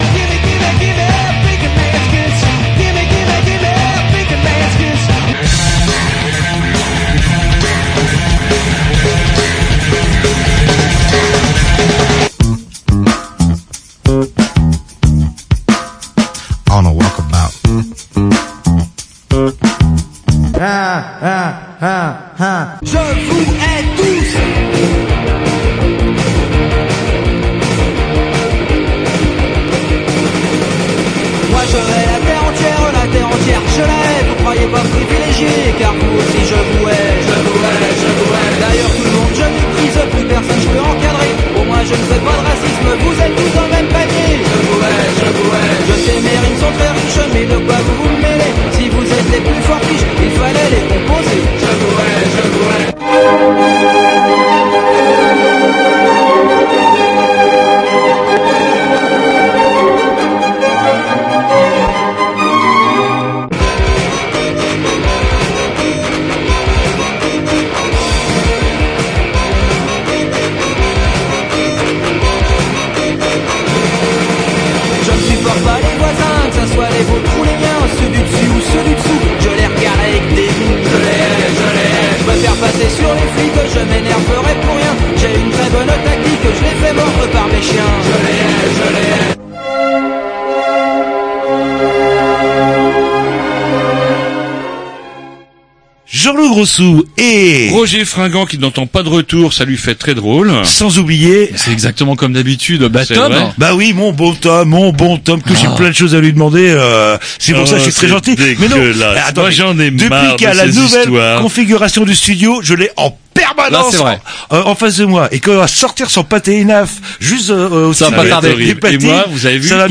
Bloge des Grignou, émission d'humeur sur la radio locale associative Canal B (94 MHz), dans la région rennaise (Rennes, France).